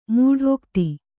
♪ mūḍhōkti